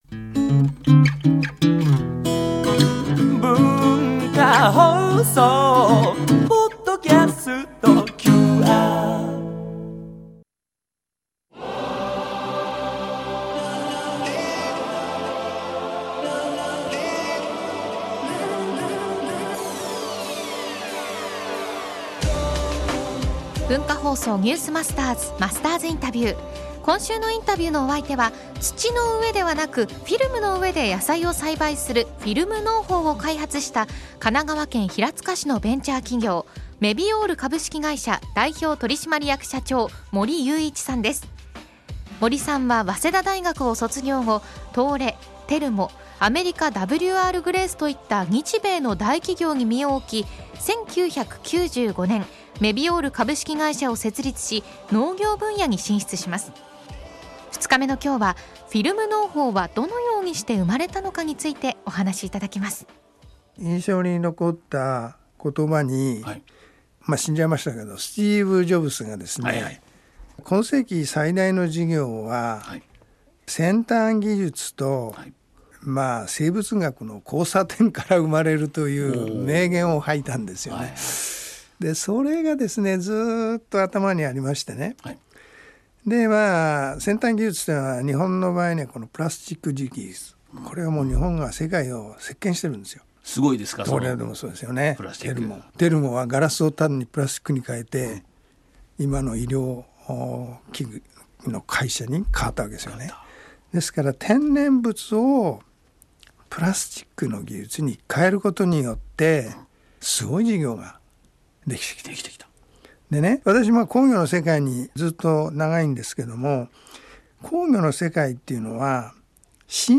毎週、現代の日本を牽引するビジネスリーダーの方々から次世代につながる様々なエピソードを伺っているマスターズインタビュー。